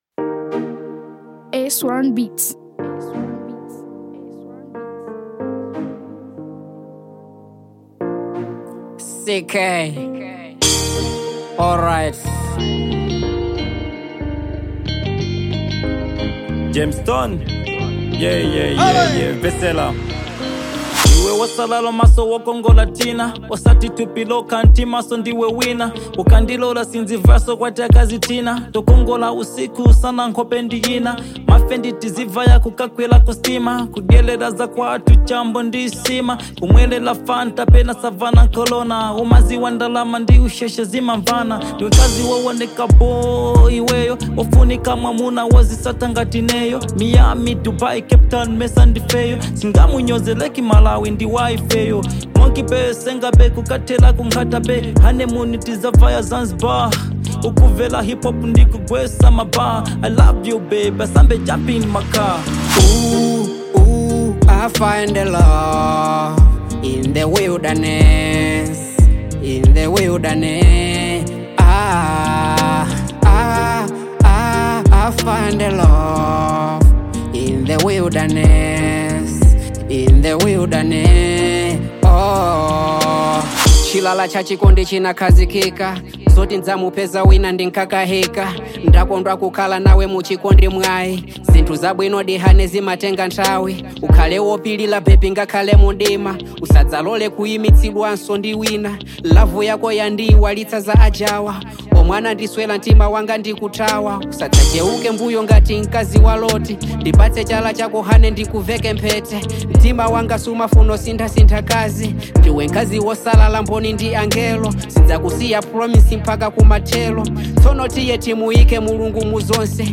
Genre : Afro-Pop